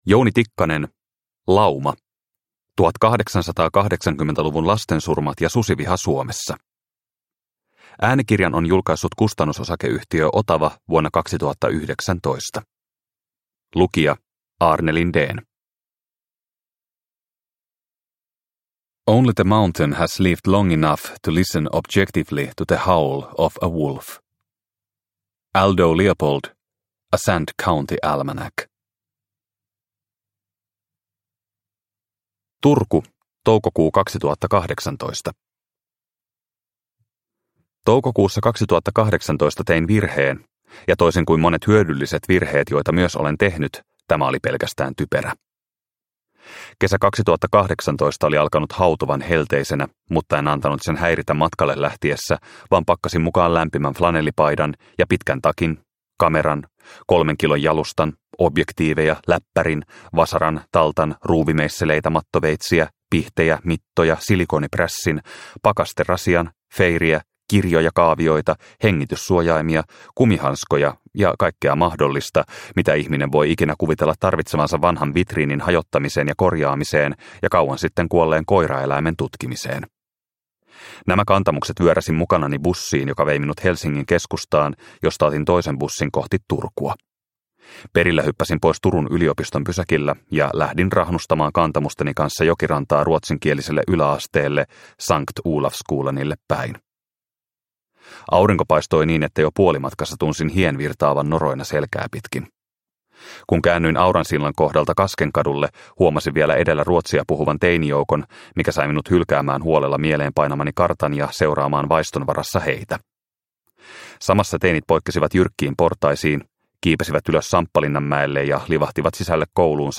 Lauma – Ljudbok – Laddas ner